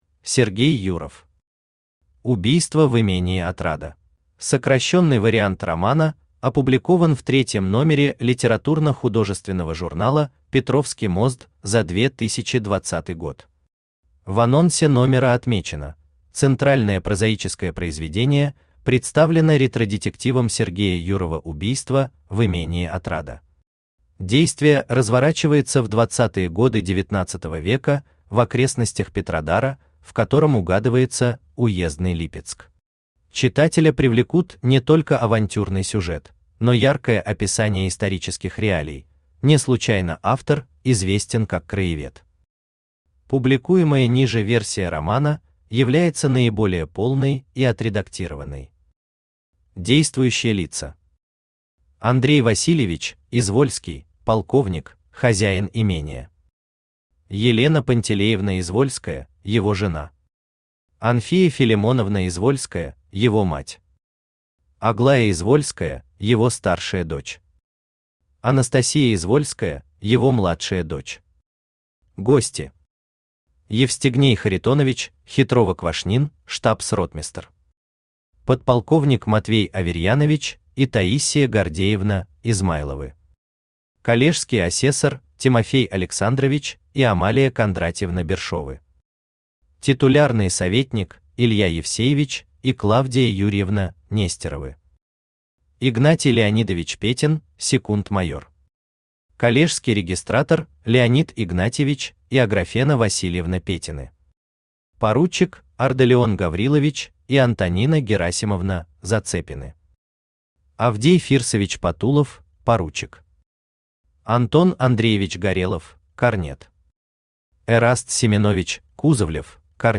Аудиокнига Убийство в имении Отрада | Библиотека аудиокниг
Прослушать и бесплатно скачать фрагмент аудиокниги